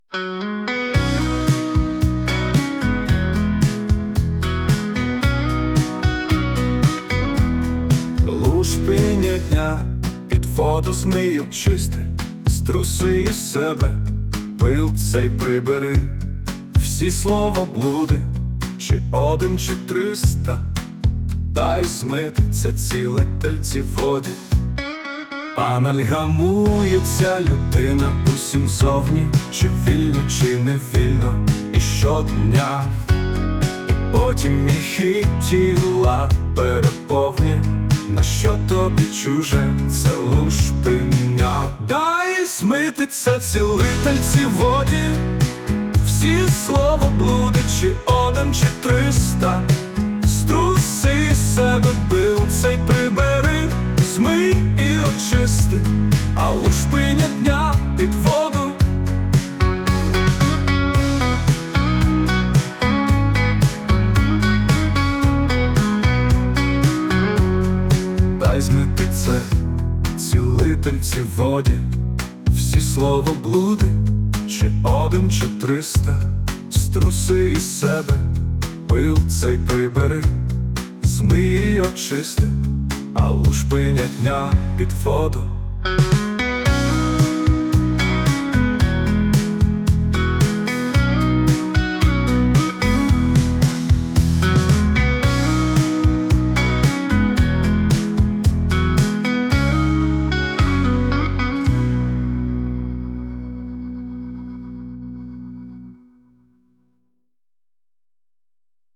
Музика і співак згенеровані
ШІ ( Штучним Інтелектом)
СТИЛЬОВІ ЖАНРИ: Ліричний